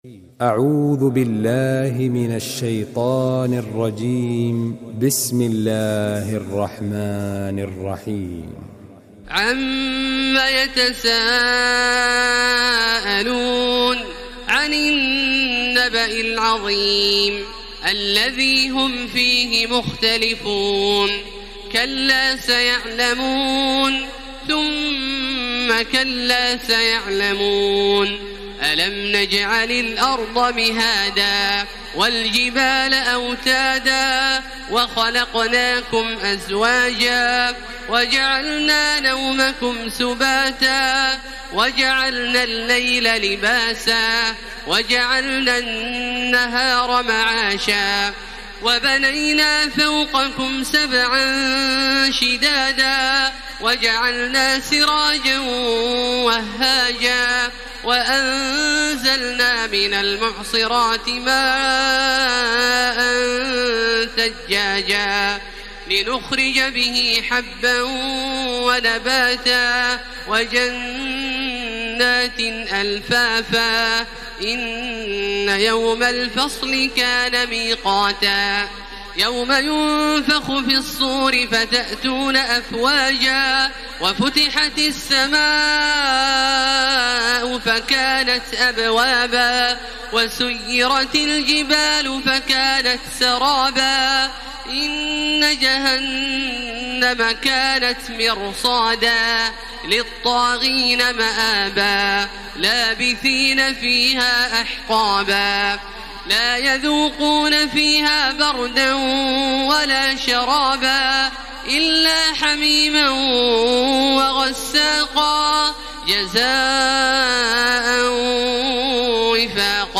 تراويح ليلة 29 رمضان 1433هـ من سورة النبأ الى الليل Taraweeh 29 st night Ramadan 1433H from Surah An-Naba to Al-Lail > تراويح الحرم المكي عام 1433 🕋 > التراويح - تلاوات الحرمين